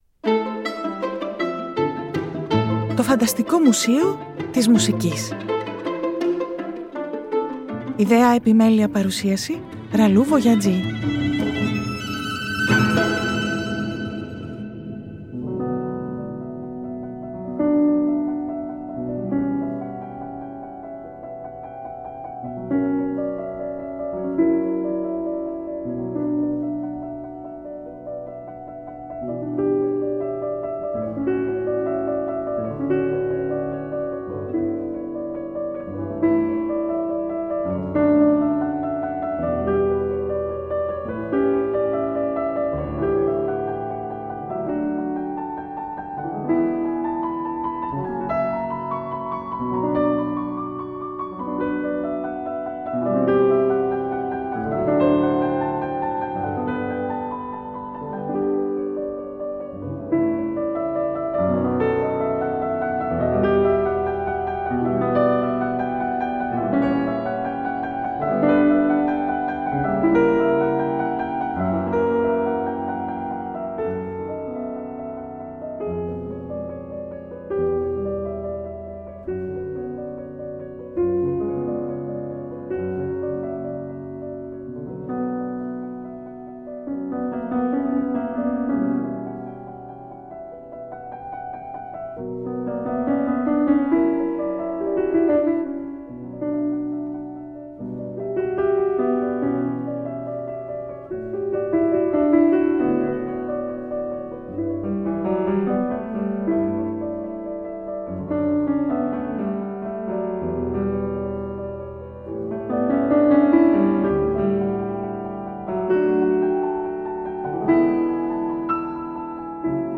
Το 2024 Το ΦΑΝΤΑΣΤΙΚΟ ΜΟΥΣΕΙΟ της ΜΟΥΣΙΚΗΣ (Τhe FANTASTIC MUSEUM of MUSIC) είναι μια ραδιοφωνική εκπομπή , ένας «τόπος» φαντασίας στην πραγματικότητα .